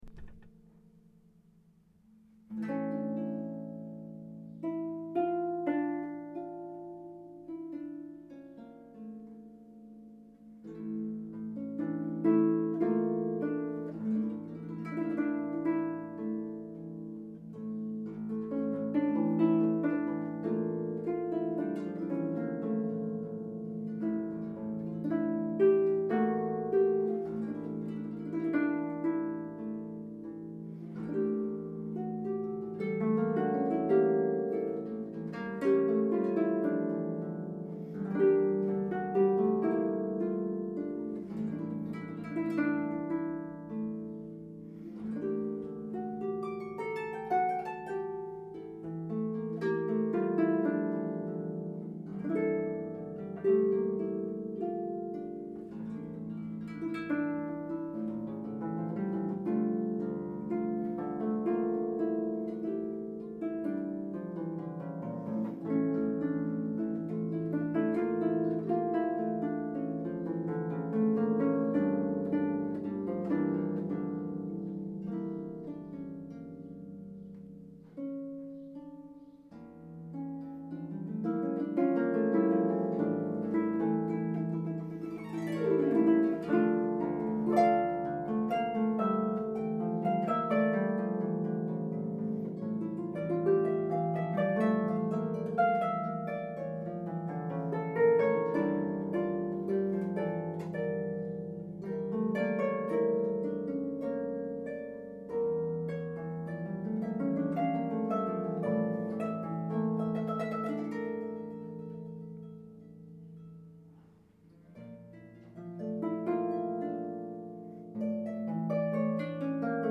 ” a traditional tune from Azerbaijan, for solo pedal harp.